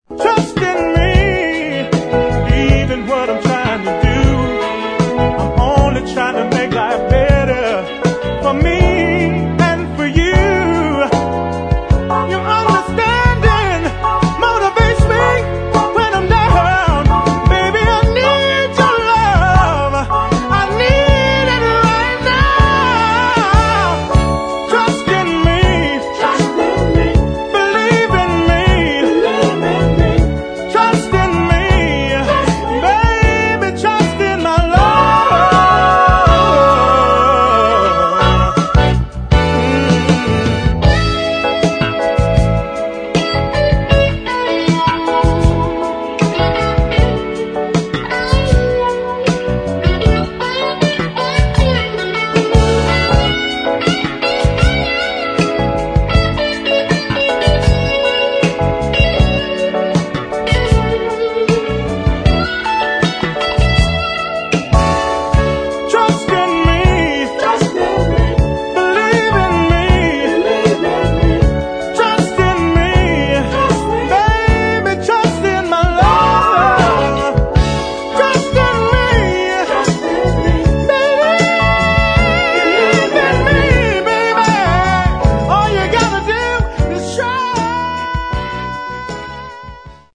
[ FUNK / SOUL / DISCO ]